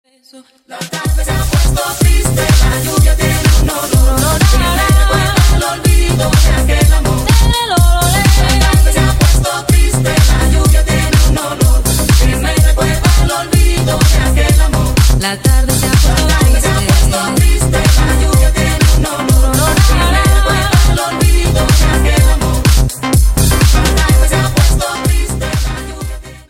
• Качество: 128, Stereo
энергичные
латинские